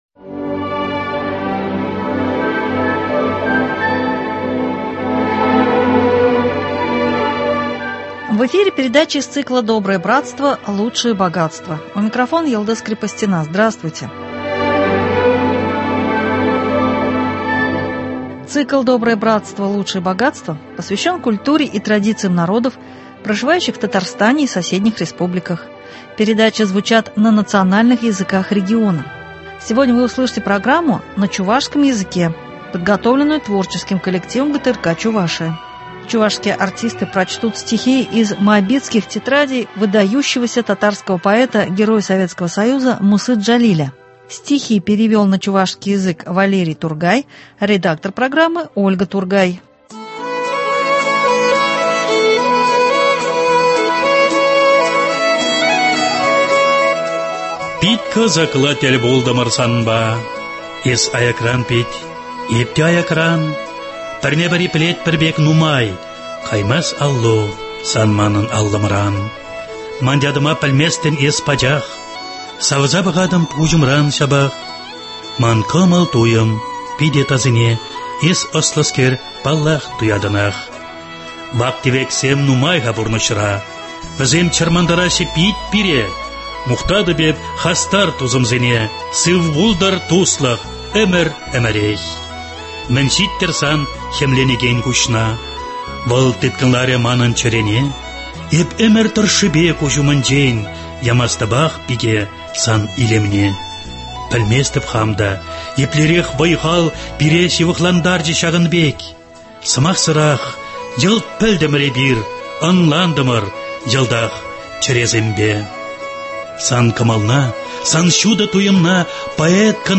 Стихи поэта- героя Мусы Джалиля в исполнении артистов Чувашии.